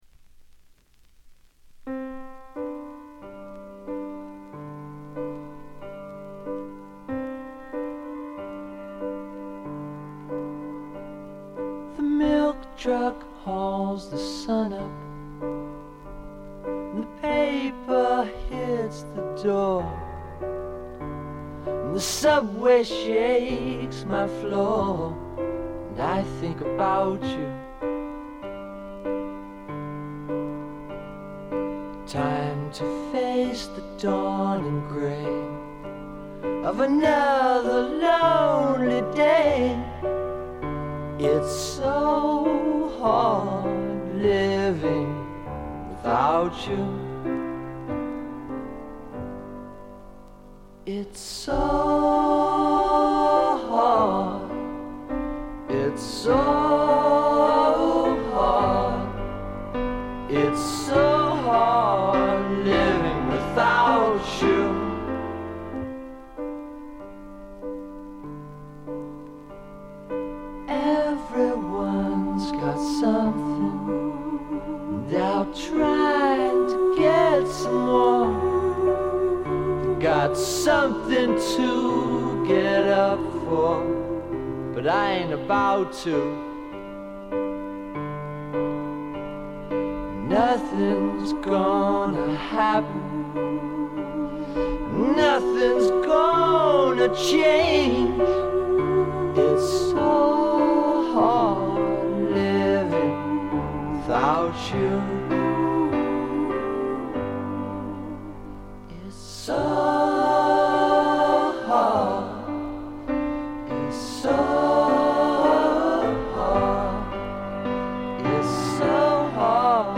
軽微なバックグラウンドノイズ程度。
試聴曲は現品からの取り込み音源です。